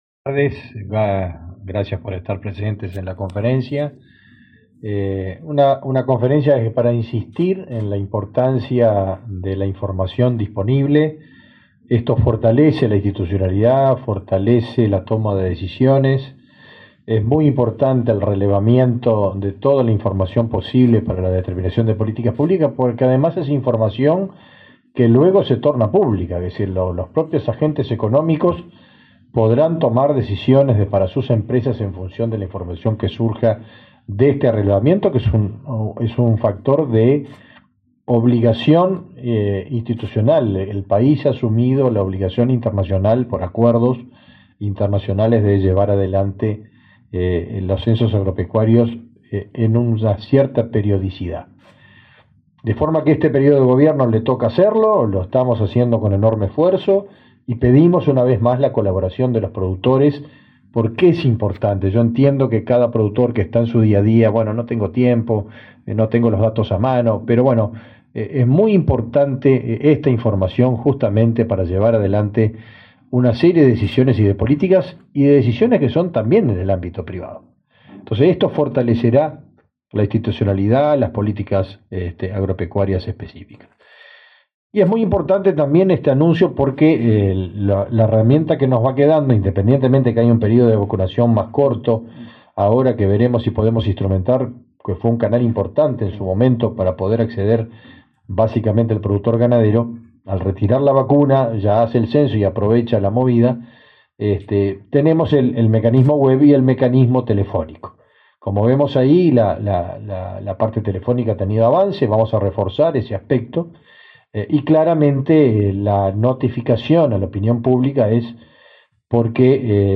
Acto por la presentación de avances del Censo General Agropecuario
Acto por la presentación de avances del Censo General Agropecuario 18/06/2024 Compartir Facebook X Copiar enlace WhatsApp LinkedIn El Ministerio de Ganadería, Agricultura y Pesca (MGAP) y el Instituto Nacional de Estadística (INE) presentaron datos y avances del Censo General Agropecuario, este 18 de junio. Participaron del evento el titular del MGAP, Fernando Mattos, y el director del INE, Diego Aboal.